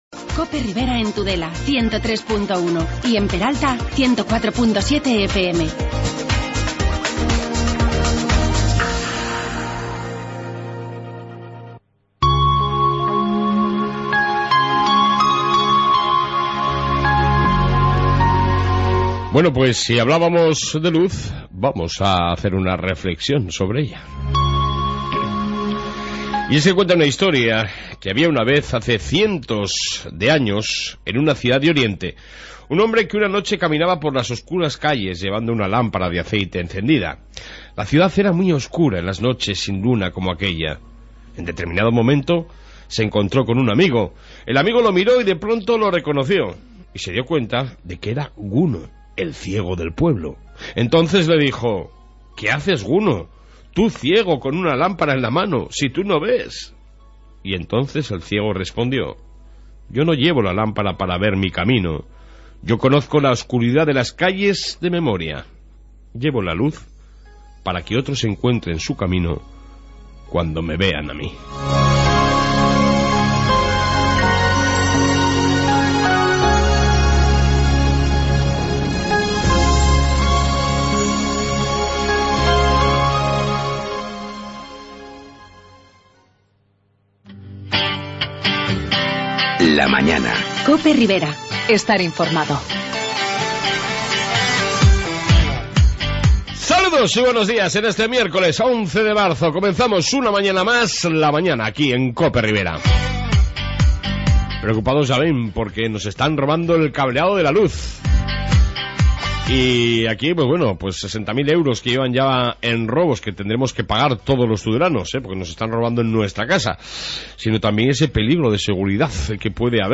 AUDIO: Informativo ribero con entrevsta sobre el robo de cobre en Tudela...